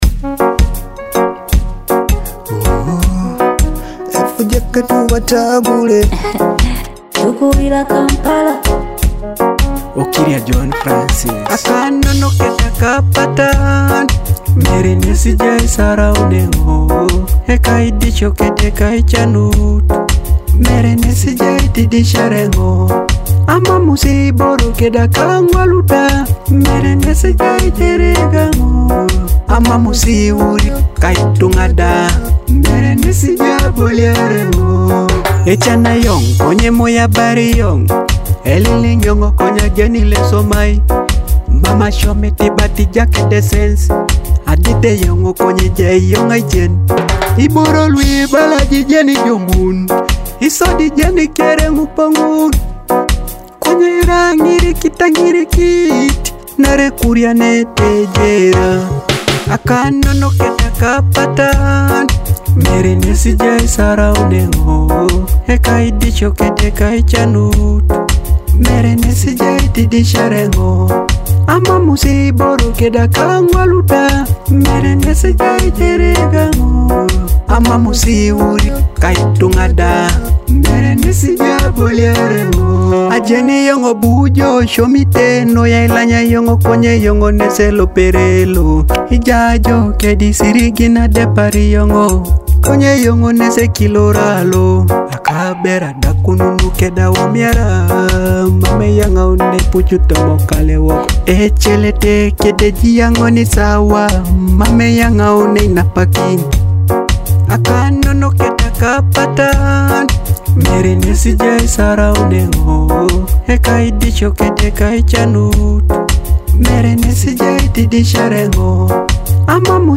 Listen to authentic Teso music online!